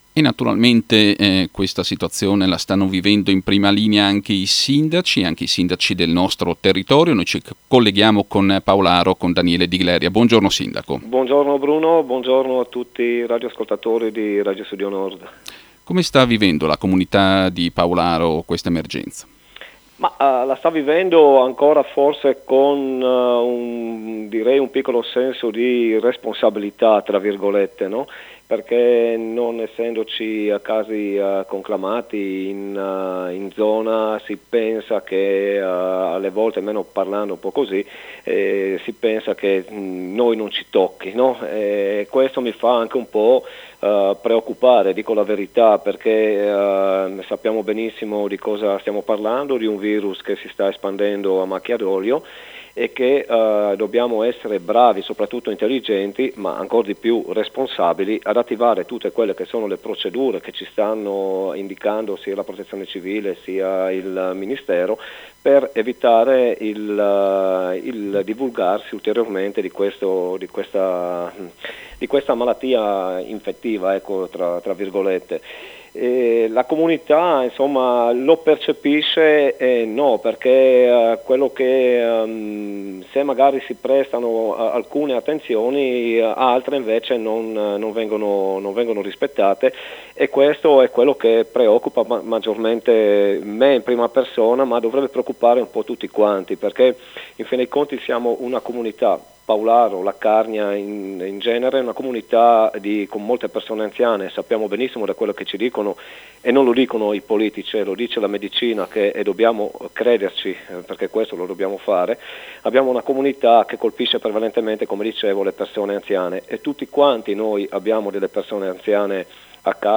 Il primo cittadino, attraverso Radio Studio Nord, ha voluto lanciare un appello sui comportamenti da tenere in questa fase di emergenza dovuta al coronavirus